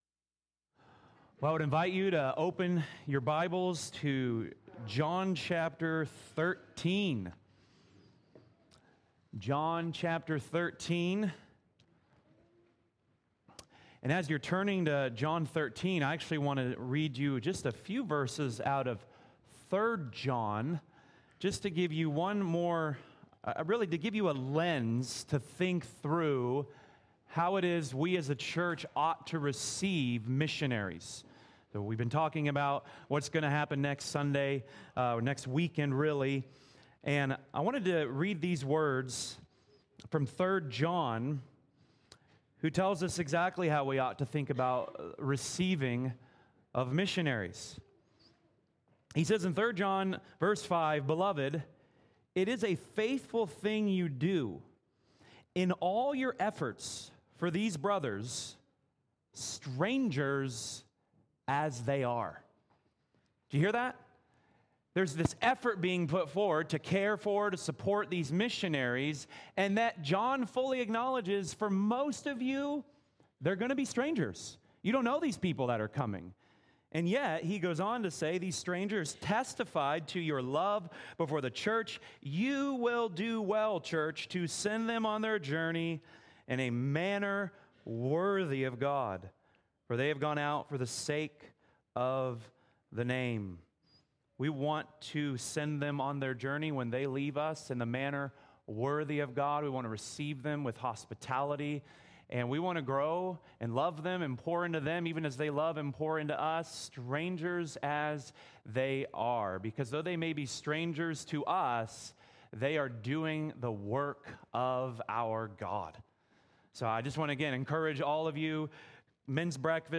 Sermons | Vine Community Church